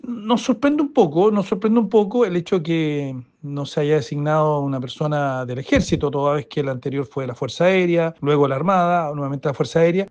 El diputado Andrés Jouannet, integrante de la comisión de Defensa de la Cámara, indicó que “no deja de llamar la atención” que no se haya designado a un alto mando del Ejército, a propósito de la rotación que se da en estos casos.